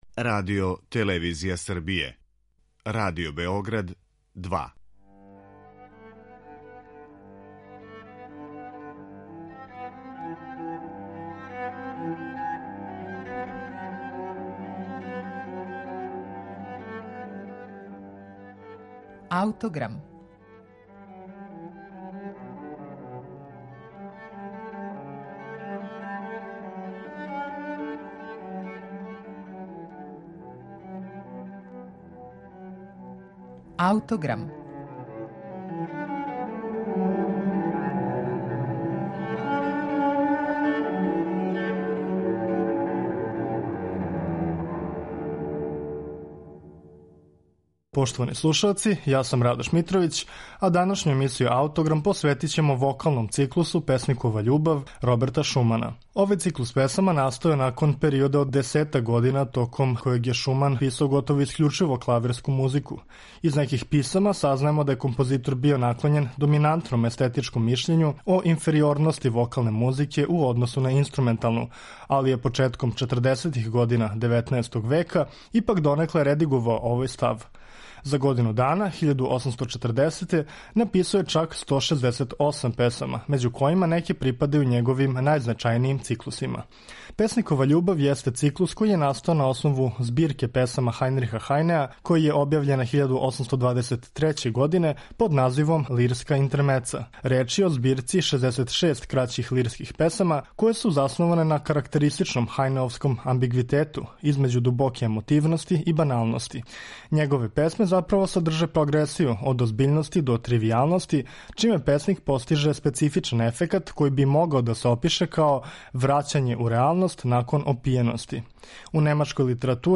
Овај вокални циклус тако сведочи о богатој музичкој имагинацији и композиторској спретности.